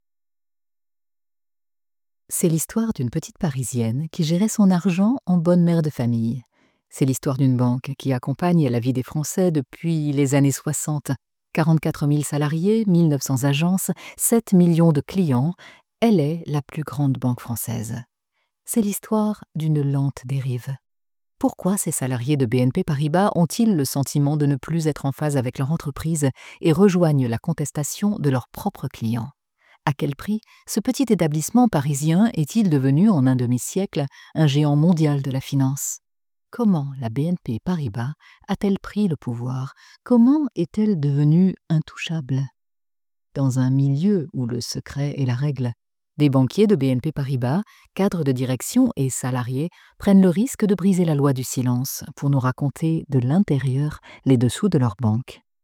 Narration - Voix amusée
- Mezzo-soprano